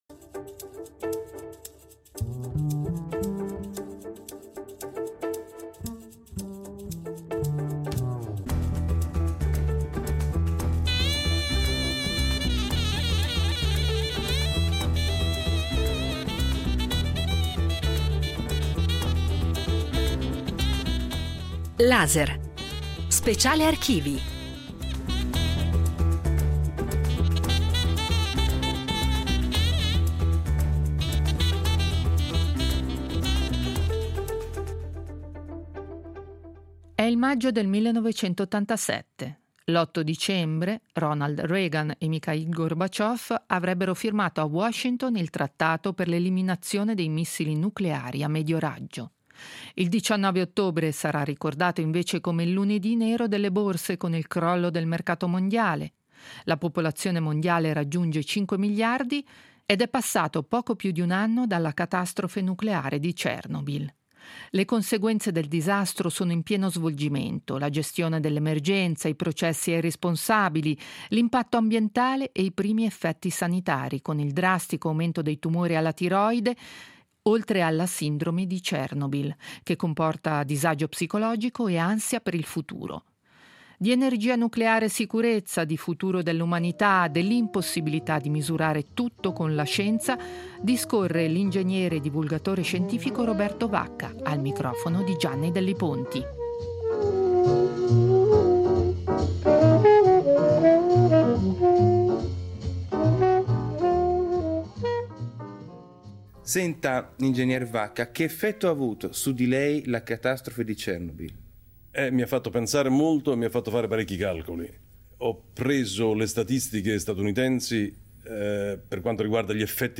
Intervista a Roberto Vacca, in collaborazione con gli Archivi RSI